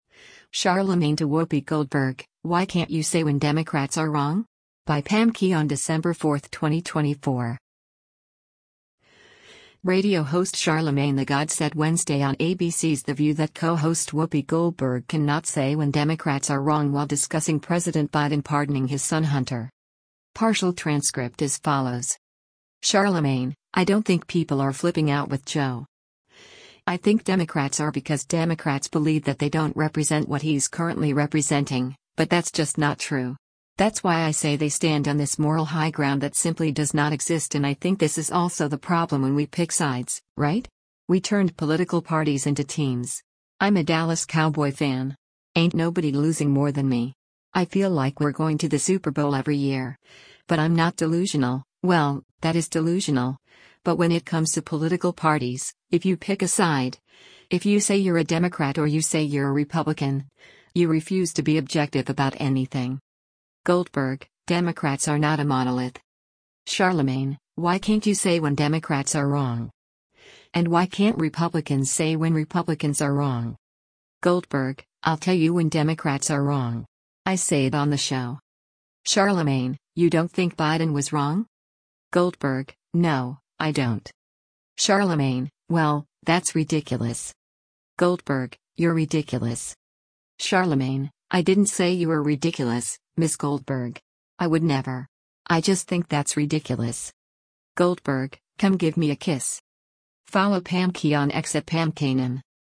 Radio host Charlamagne tha God said Wednesday on ABC’s “The View” that co-host Whoopi Goldberg can not say when Democrats are wrong while discussing President Biden pardoning his son Hunter.